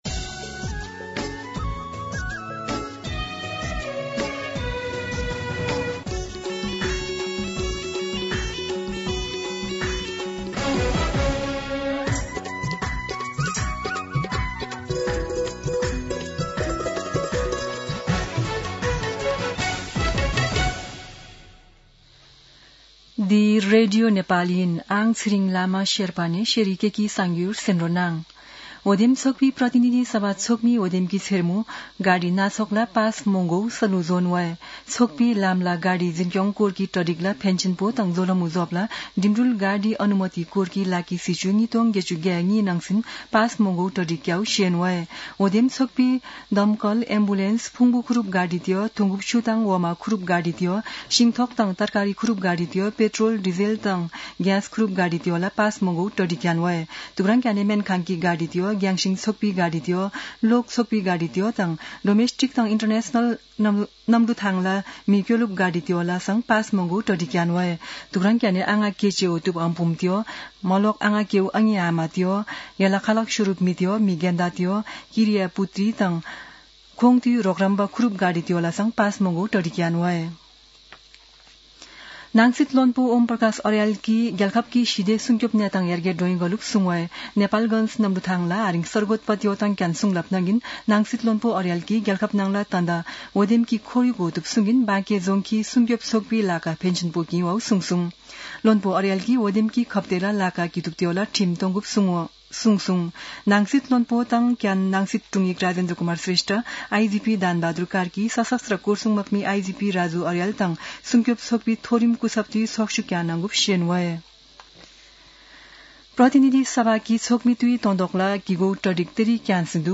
शेर्पा भाषाको समाचार : १७ फागुन , २०८२
Sherpa-News-17.mp3